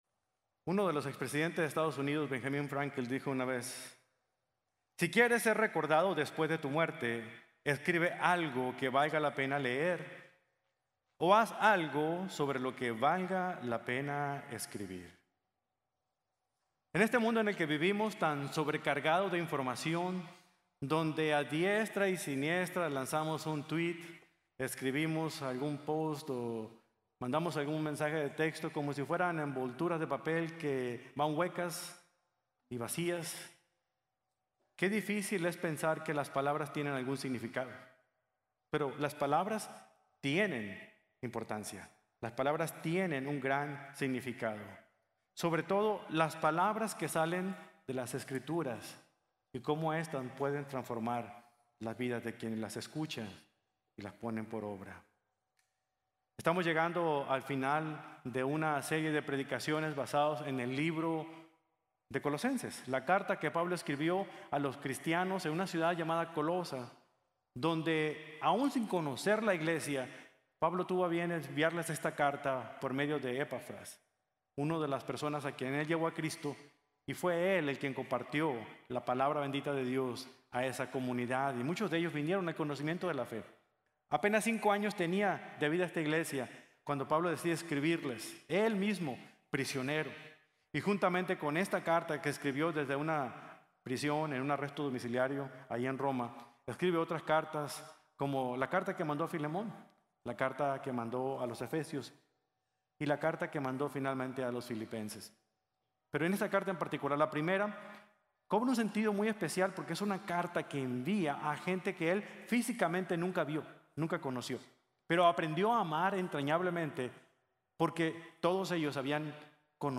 Aprovechando las Oportunidades | Sermon | Grace Bible Church